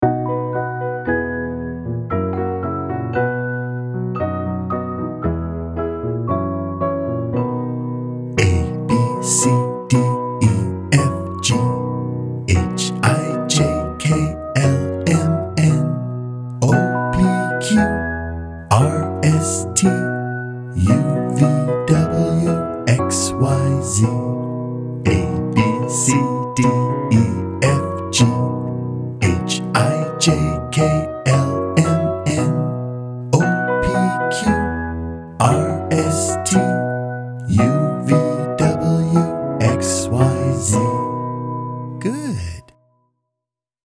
はやさは３種類！
ABC-Song-REGULAR.mp3